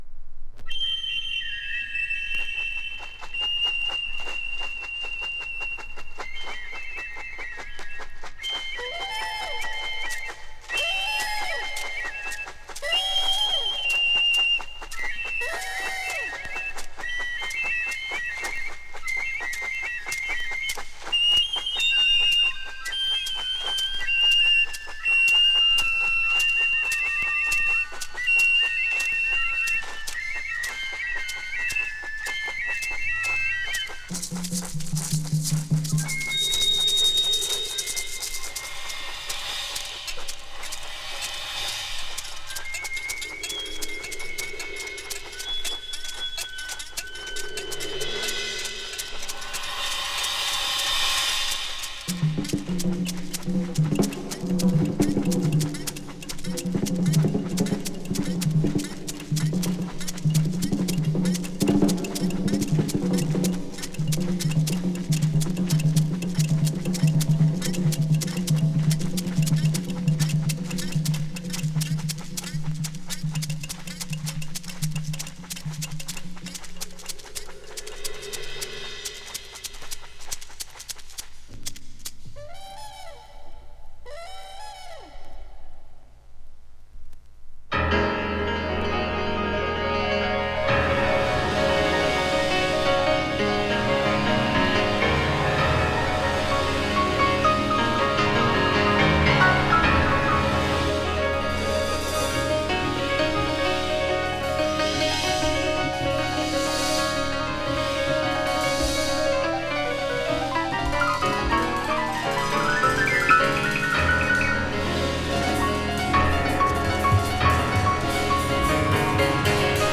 フルートやパーカッション